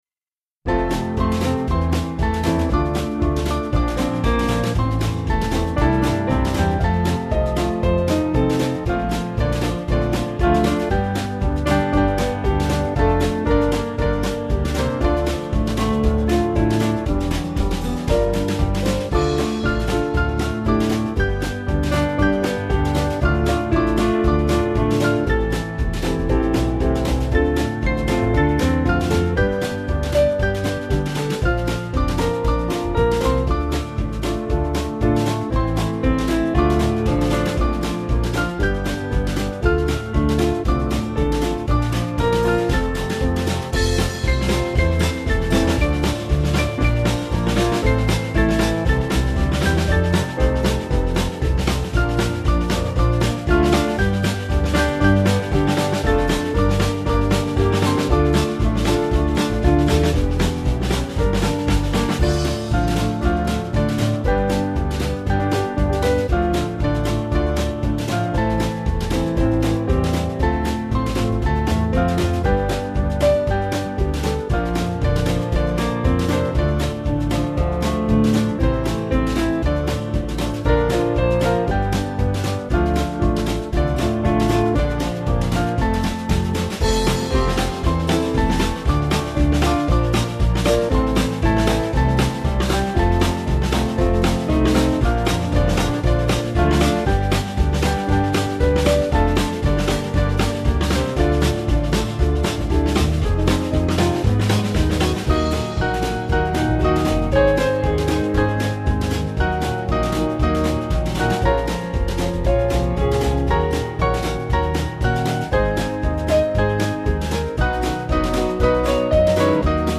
Small Band
(CM)   3/Dm-Ebm